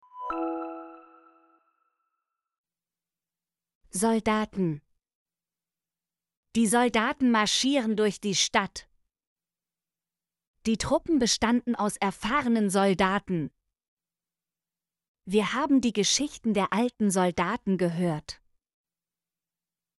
soldaten - Example Sentences & Pronunciation, German Frequency List